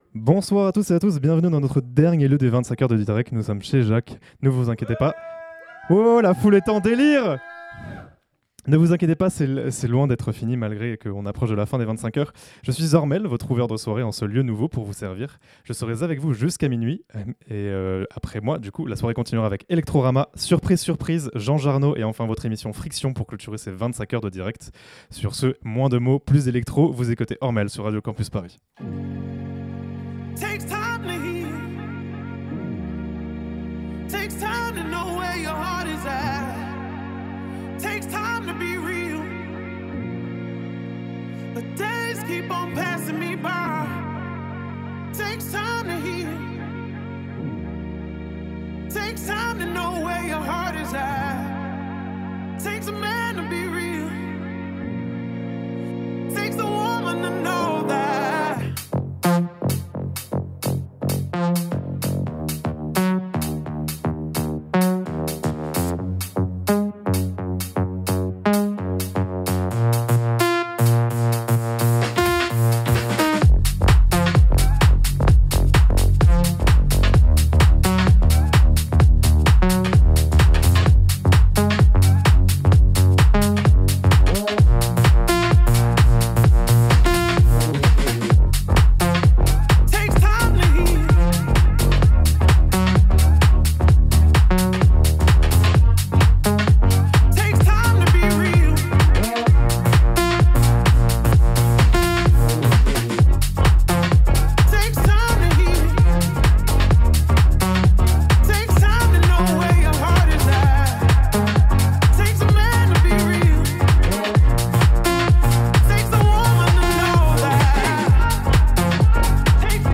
Le set d'ouverture de la soirée chez Jacques.